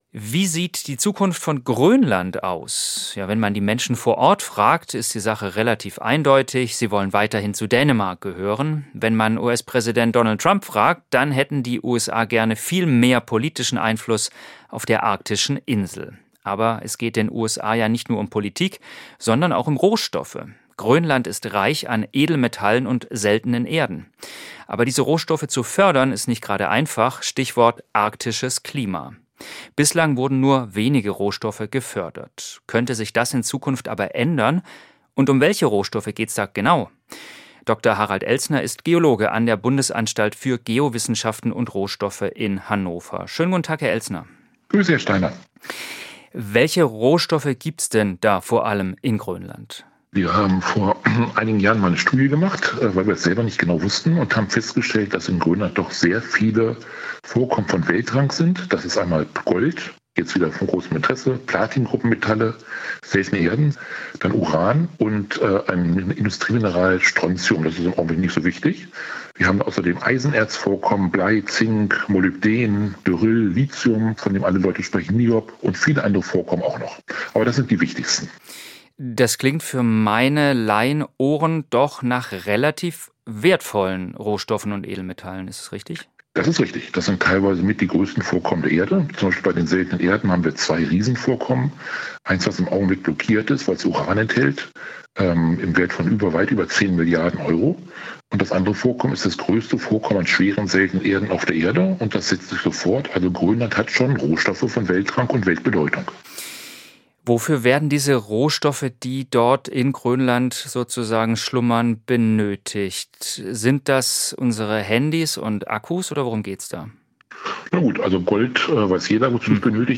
Gespräch mit dem Geologen